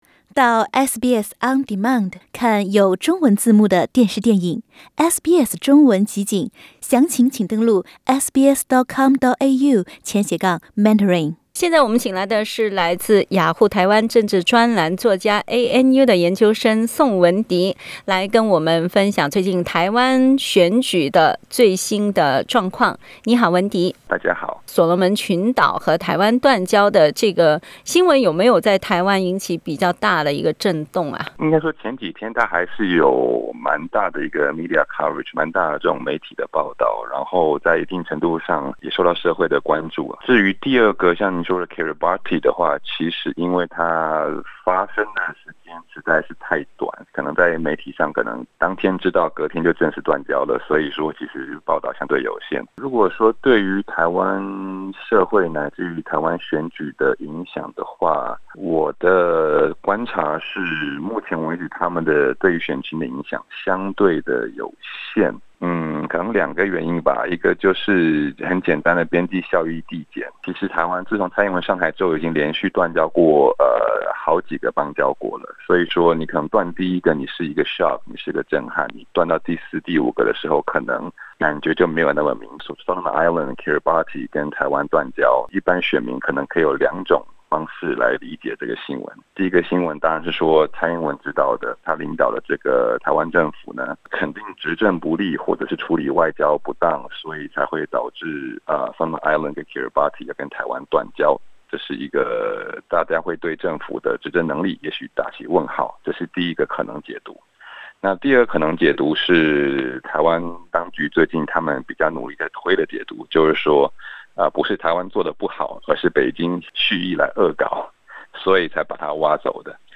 Source: EPA SBS Mandarin View Podcast Series Follow and Subscribe Apple Podcasts YouTube Spotify Listnr Download (16.78MB) Download the SBS Audio app Available on iOS and Android 所罗门群岛和台湾断交，是否影响台湾的总统选举走向？
（本节目为嘉宾观点，不代表本台立场。）